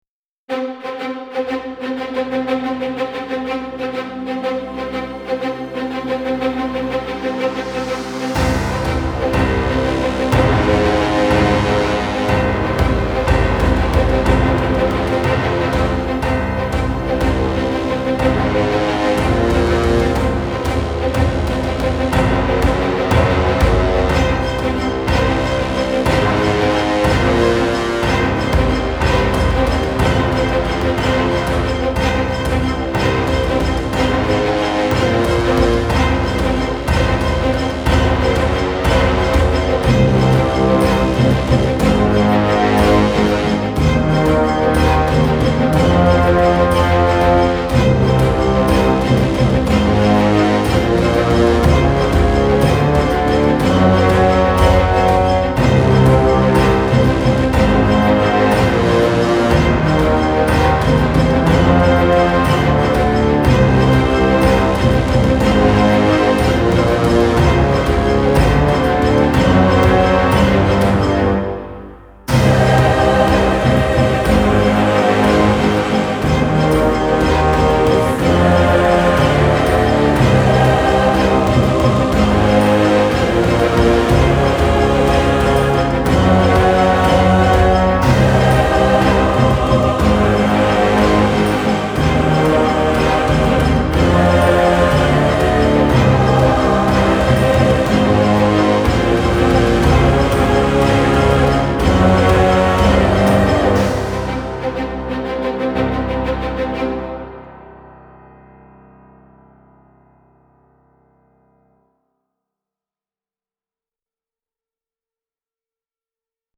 Genre(s): › Epic